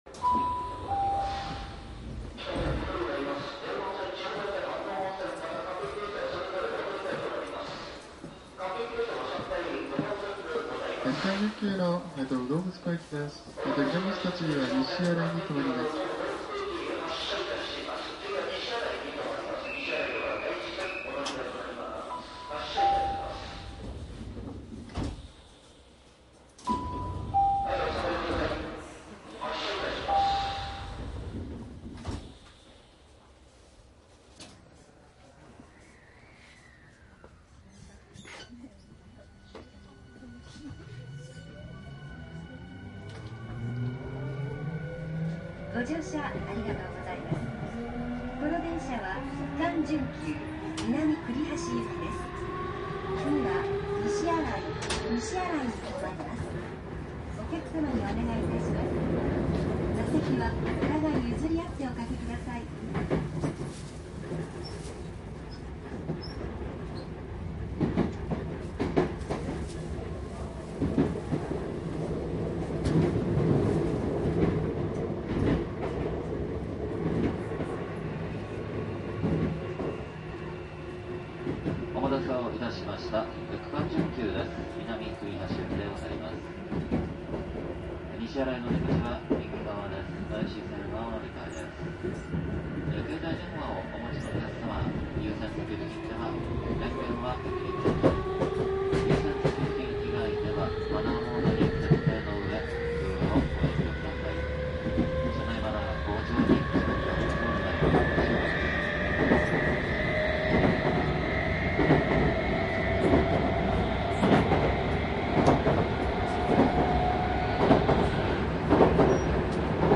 東武スカイツリーライン「北千住～南栗橋」 5000系電車  走行音ＣD♪
田園都市線用５０００系最初の6本が導入された時の録音です。夕方の録音ですので越谷あたりまでは客が乗ってます。
せんげん台で停車中に子供の声が大きく入ったのでその部分をカットしています。
※走行音については東横線の50番台と同じです。使用マイクも東横線と同じですので録音状況は東横線のサンプル音声もご参照下さい。
マスター音源はデジタル44.1kHz16ビット（マイクＥＣＭ959）で、これを編集ソフトでＣＤに焼いたものです。